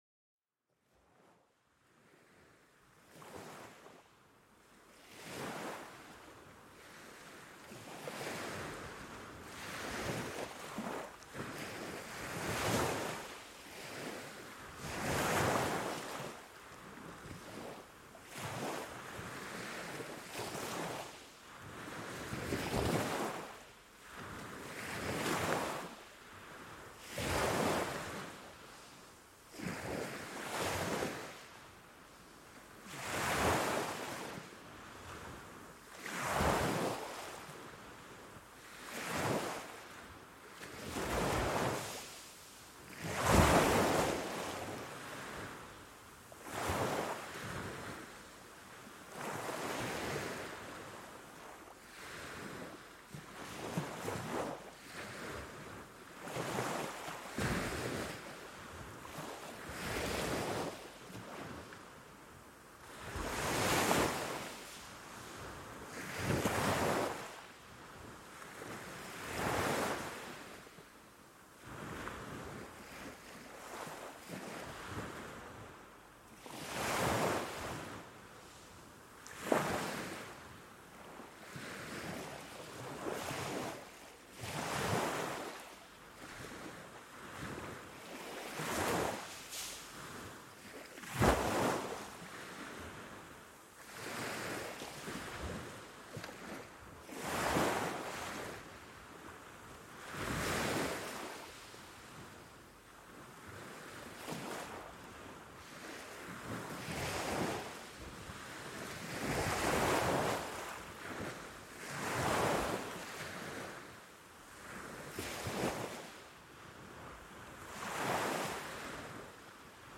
Les vagues de la mer pour apaiser l'esprit